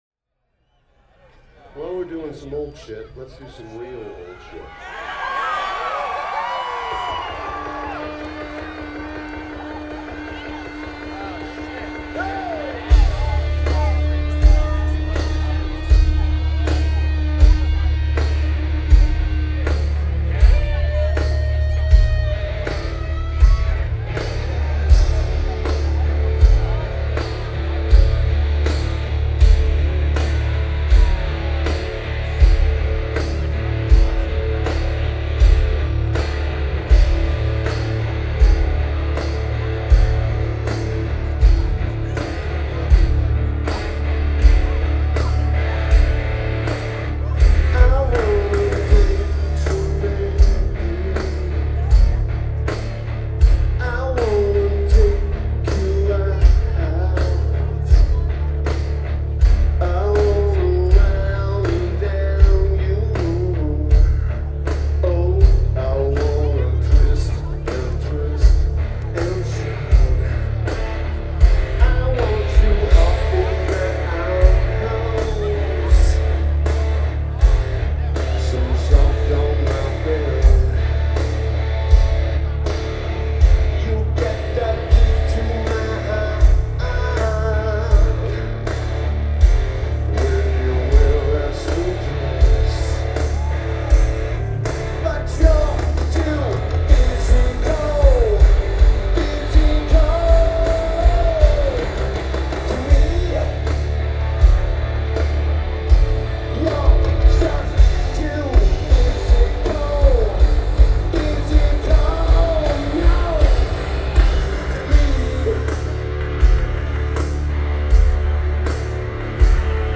Ascend Amphitheater
Lineage: Audio - AUD (DPA4061s + Tascam DR2D)